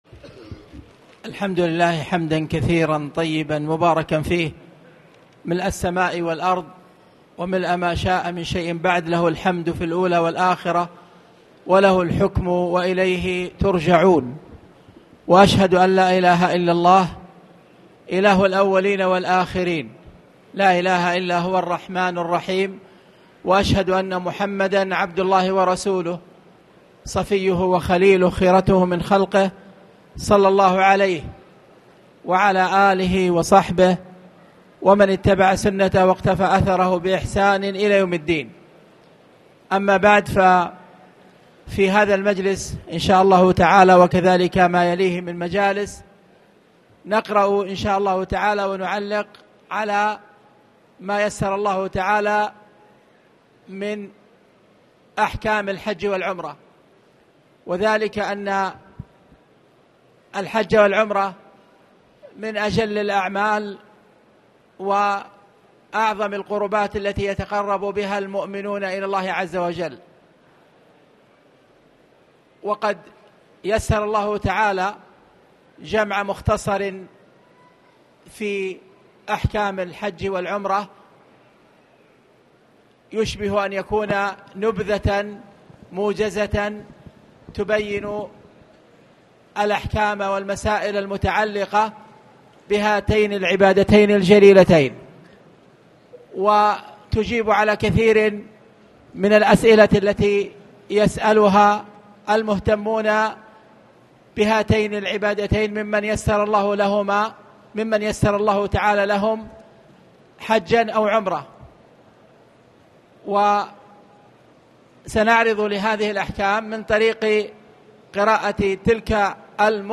تاريخ النشر ٢٧ شوال ١٤٣٨ هـ المكان: المسجد الحرام الشيخ: فضيلة الشيخ أ.د. خالد بن عبدالله المصلح فضيلة الشيخ أ.د. خالد بن عبدالله المصلح صفة الحج والعمرة